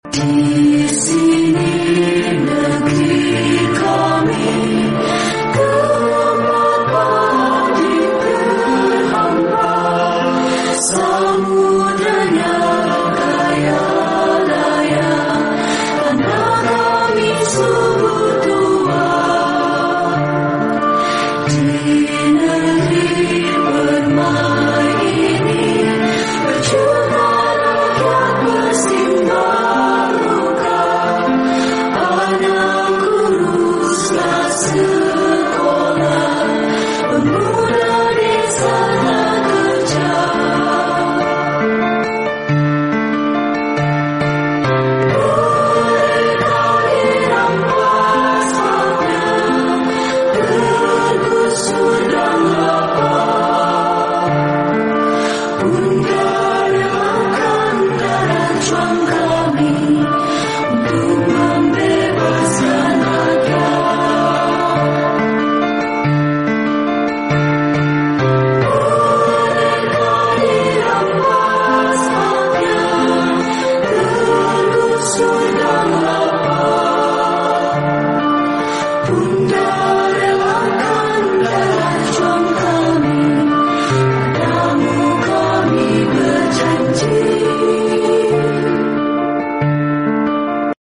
Massa aksi demo yang tergabung sound effects free download By forumkeadilantv 10 Downloads 9 hours ago 73 seconds forumkeadilantv Sound Effects About Massa aksi demo yang tergabung Mp3 Sound Effect Massa aksi demo yang tergabung dalam Badan Eksekutif Mahasiswa (BEM) Seluruh Indonesia (SI) memasuki Markas Kepolisian Polda Metro Jaya, Jl. Sudirman, Jakarta Selatan, pada 29/8. Para masa aksi tersebut meminta Kapolda Metro Jaya Irjen Asep Edi Suheri untuk menemui mahasiswa dan mengundurkan diri.